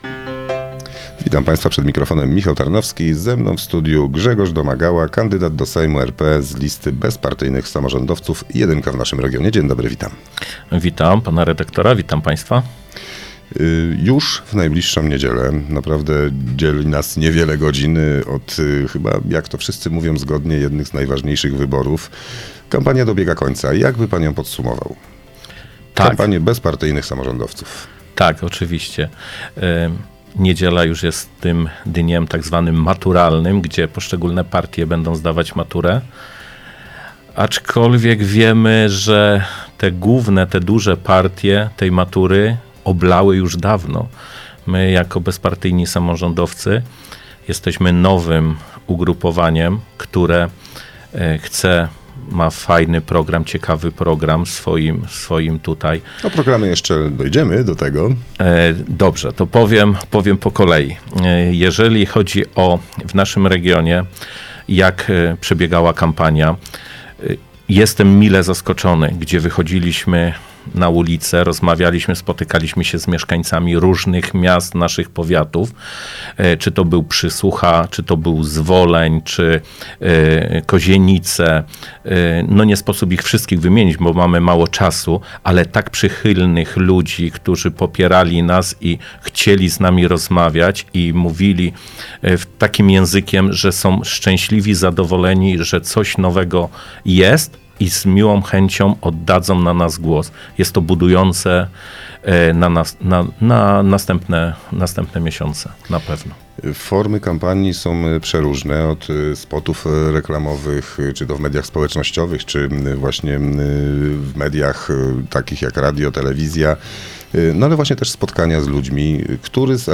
w studiu Radia Radom